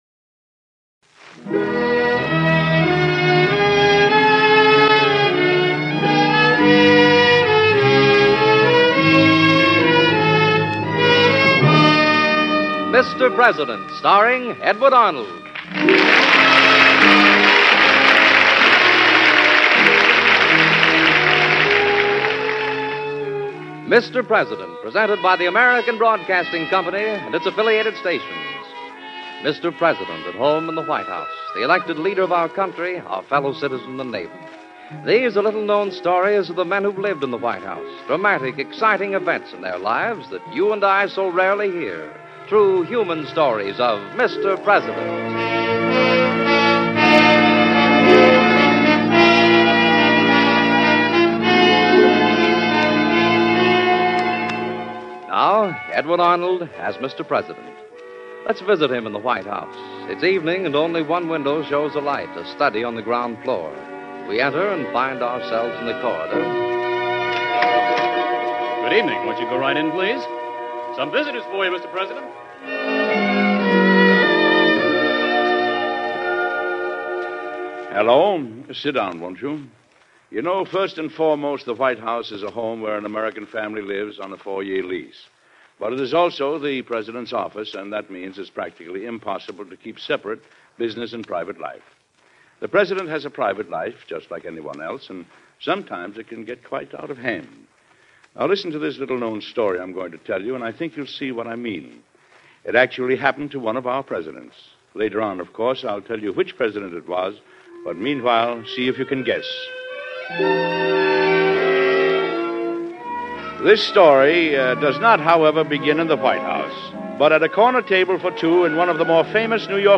Mr. President, Starring Edward Arnold